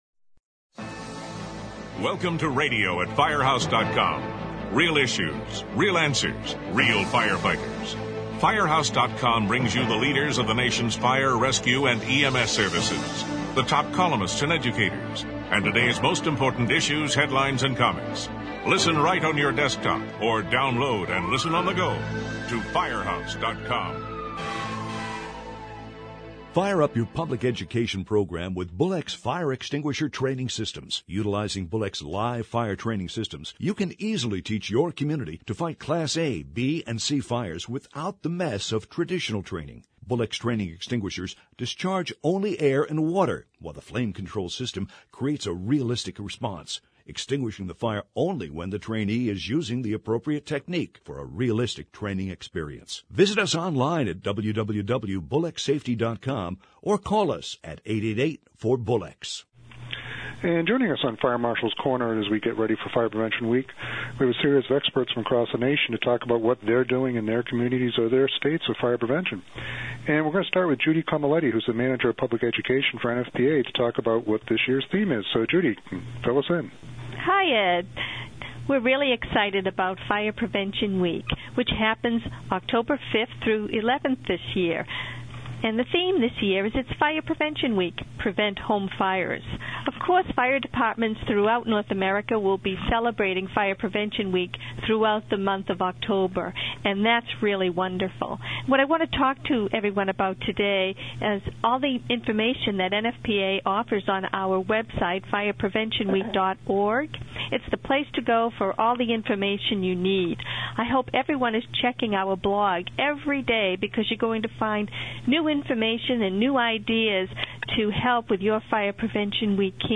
Four fire prevention experts from across the nation took time to talk about the creative programs they are doing to help educate the public and make them aware of what they can do to protect themselves.